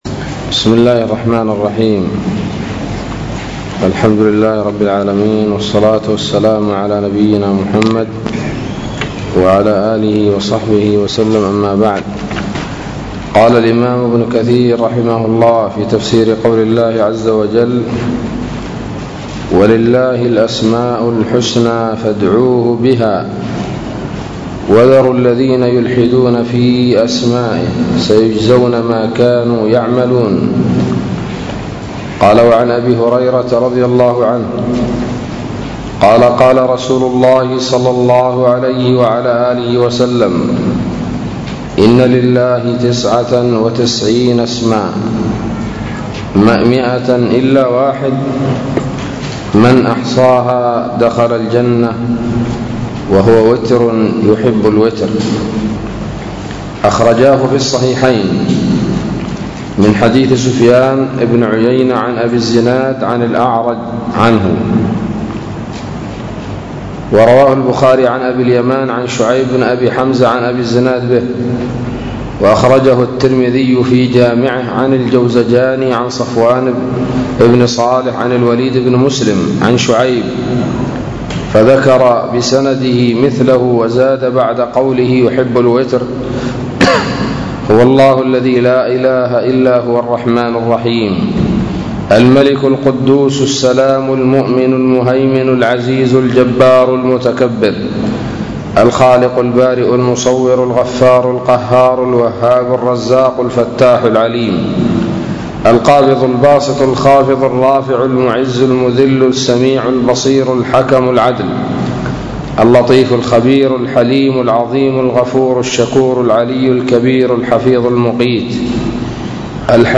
الدرس الثامن والستون من سورة الأعراف من تفسير ابن كثير رحمه الله تعالى